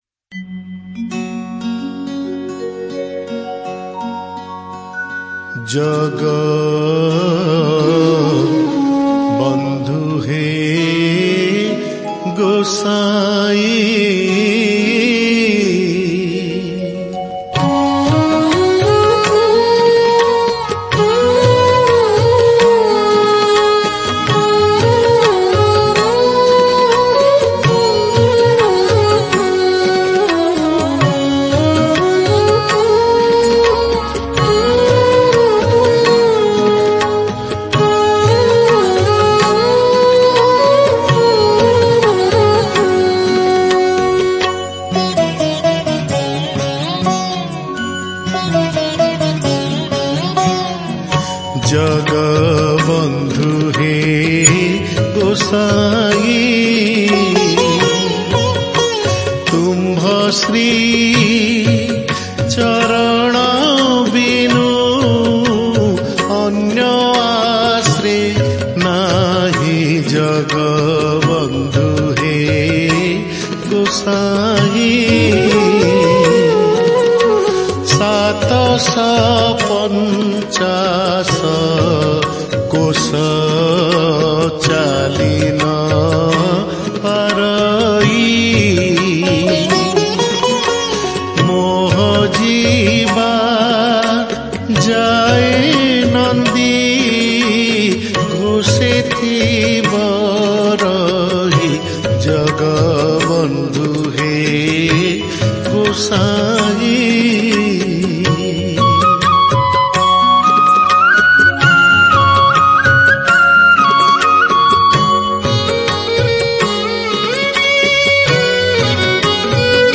Oriya Bhajan